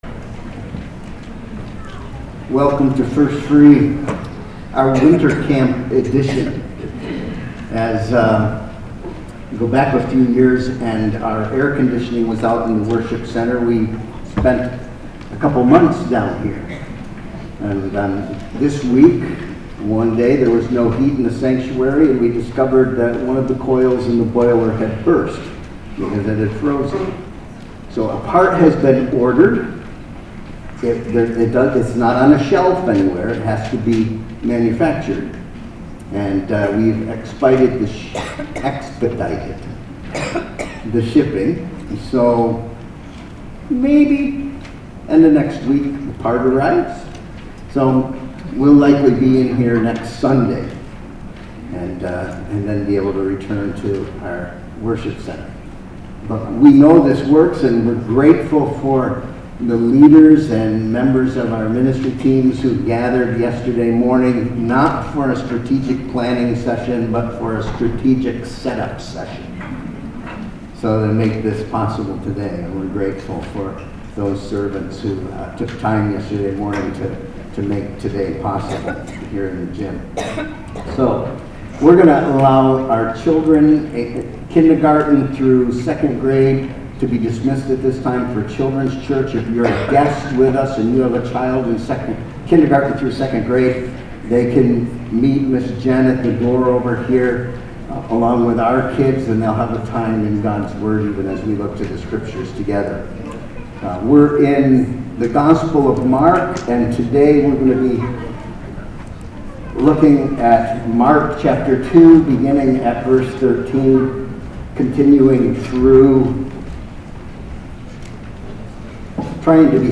Sermon Sentence: Jesus serves people in the face of increasing opposition by responding with hope-filled answers.